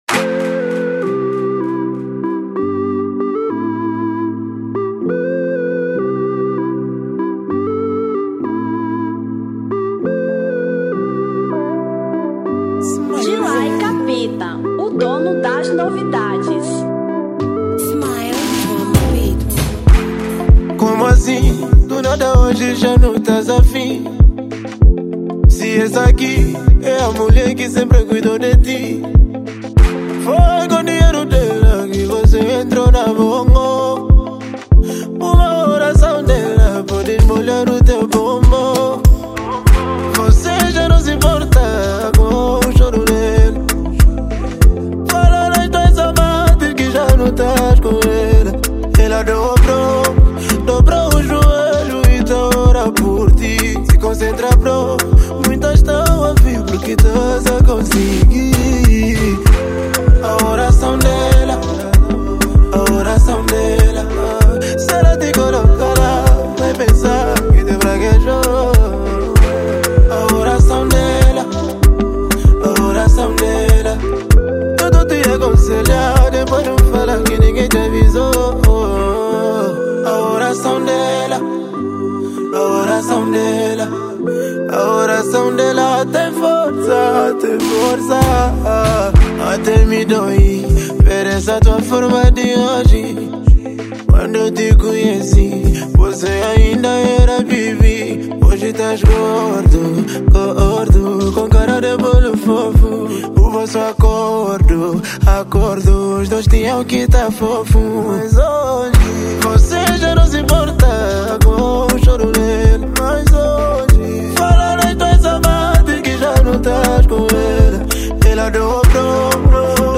Afro Pop 2024